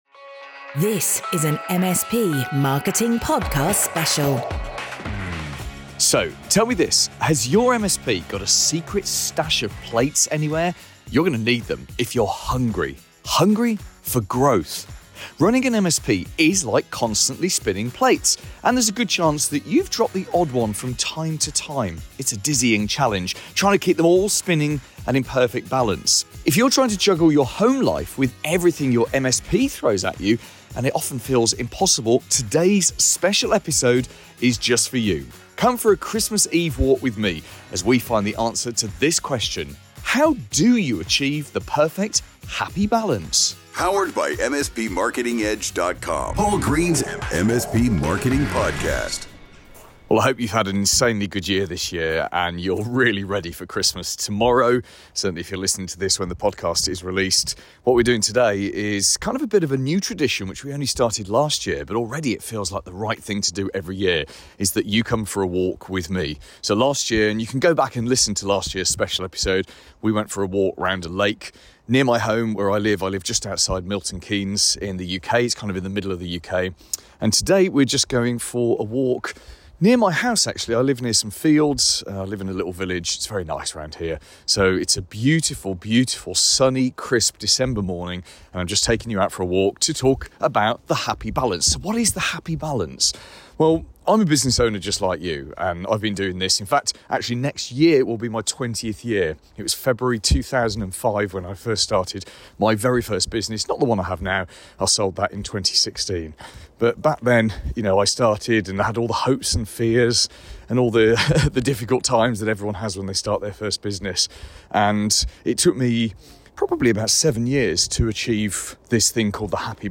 This week I’m taking you for a walk near my home.